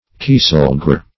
Kieselguhr \Kie"sel*guhr`\, n. [G., fr. kiesel flint + guhr an